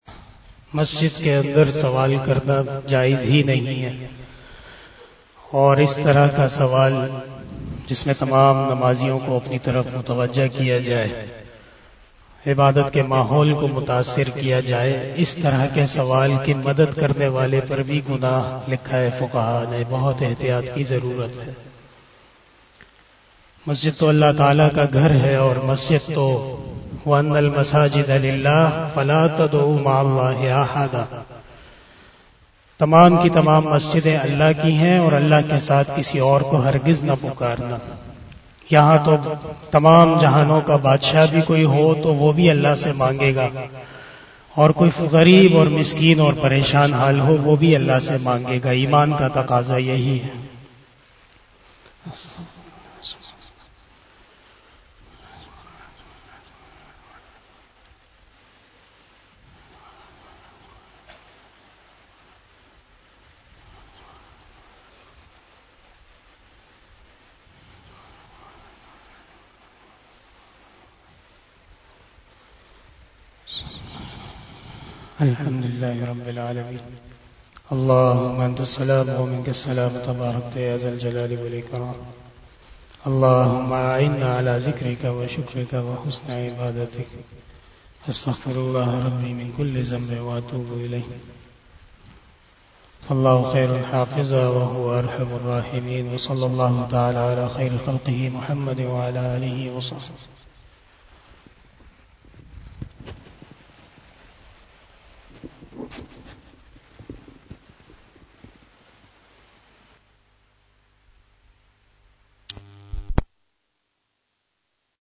015 After Asar Namaz Bayan 26 February 2022 ( 24 Rajab ul Murajjab 1443HJ) Saturday